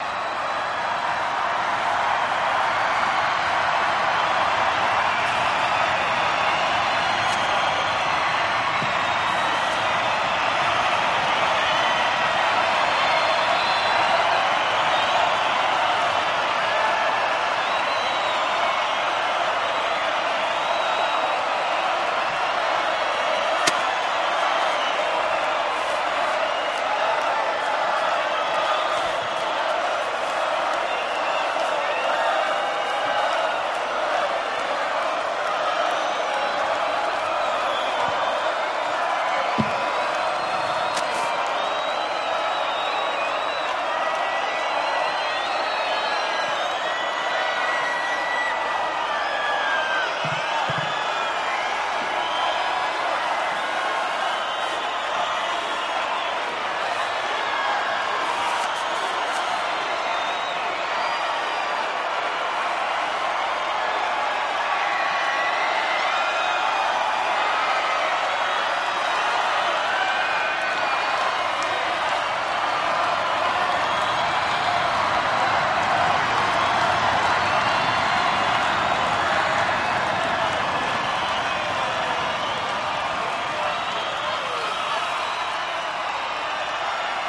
Crowd Hush and Constant Long Cheers, Paris